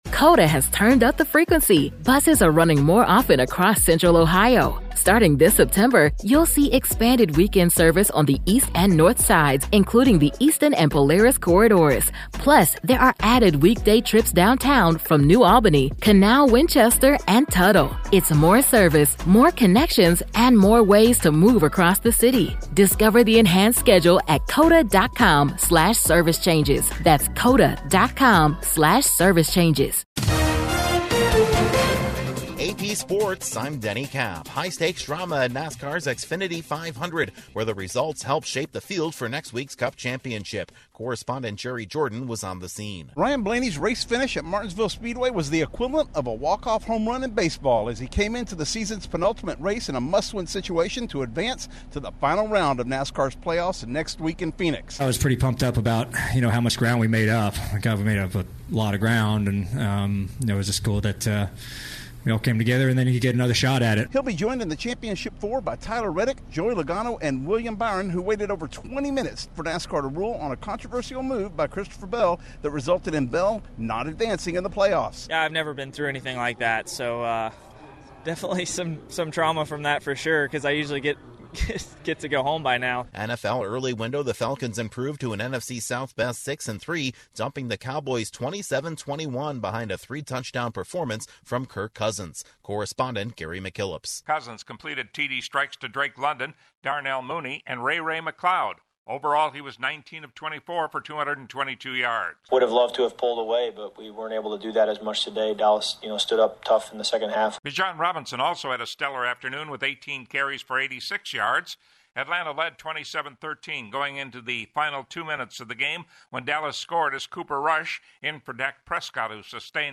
Sports News